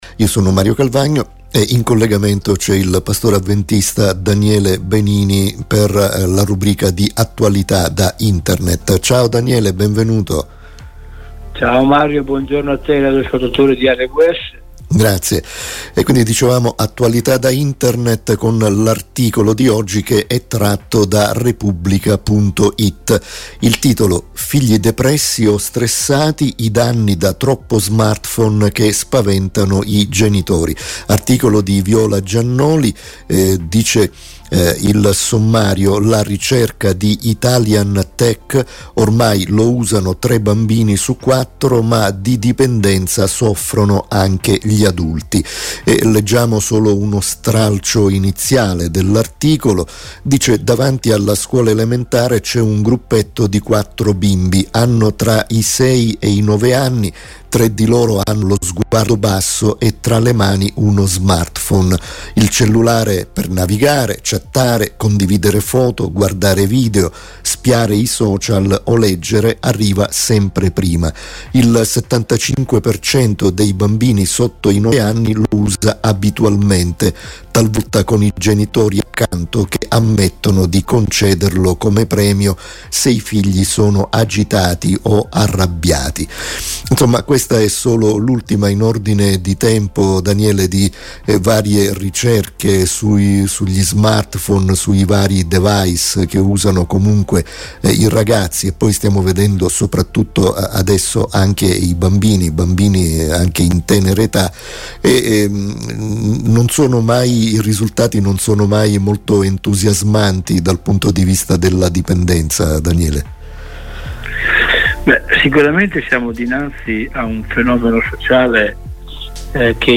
pastore avventista.